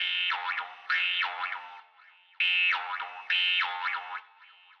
描述：西伯利亚犹太人的竖琴
Tag: 100 bpm House Loops Woodwind Loops 826.96 KB wav Key : Unknown